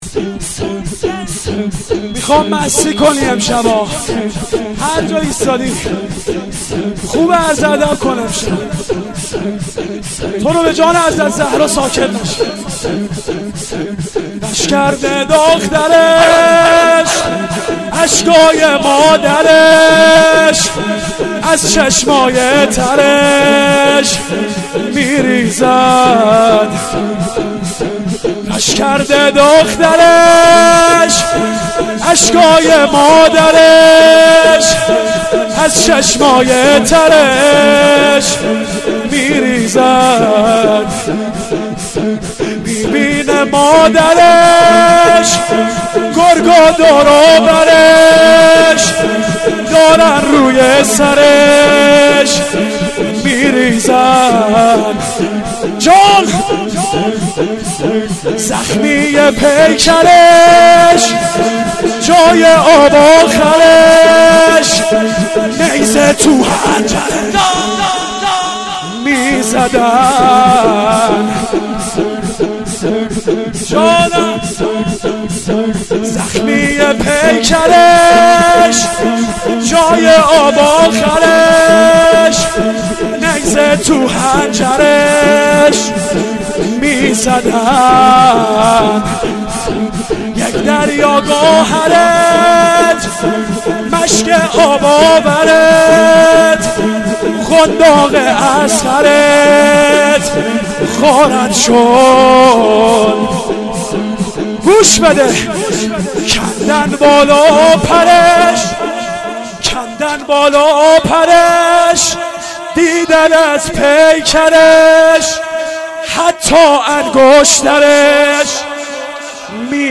غش کرده دخترش اشکاي مادرش ... شور و حروله و لطمه زني ...
مداحی ذاکر اهل بیت